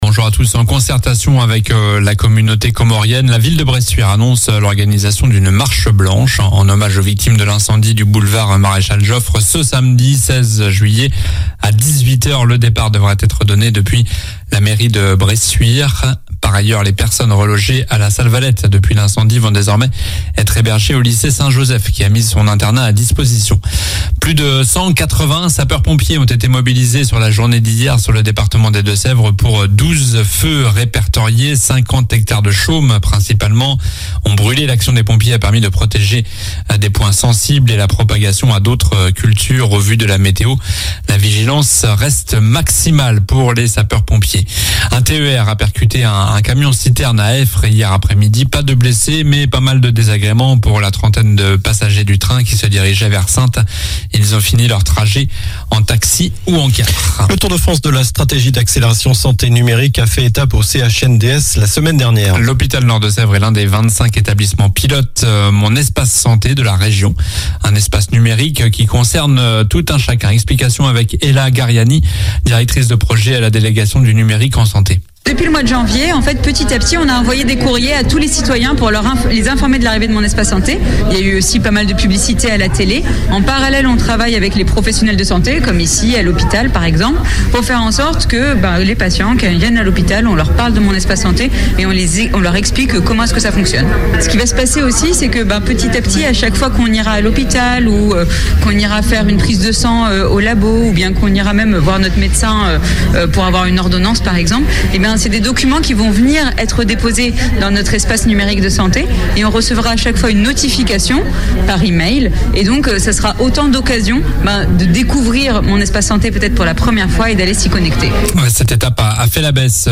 Journal du mardi 12 juillet (matin)